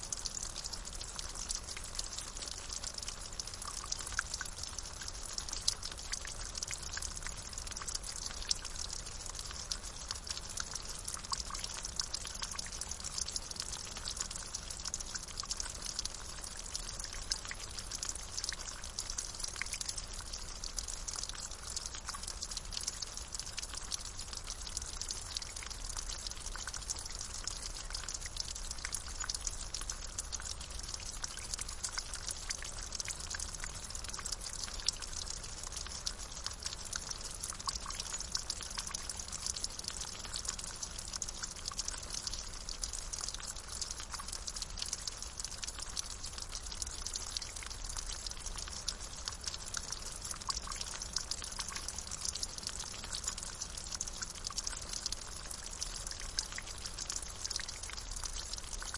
描述：放松浮水流。用这条小溪的声音梦想着
Tag: 小溪 河流 放松 液体 滴流